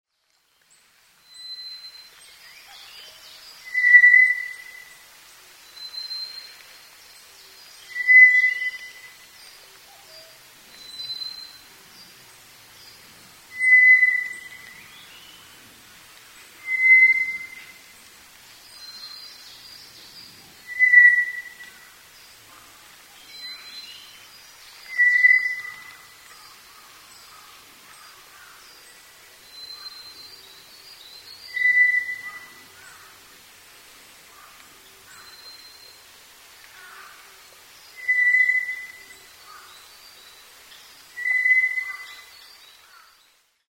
toratsugumi_s1.mp3